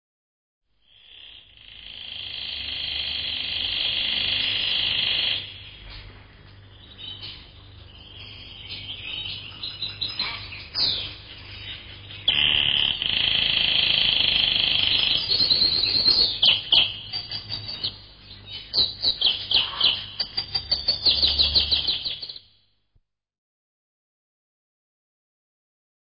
uccelli_birds02.wav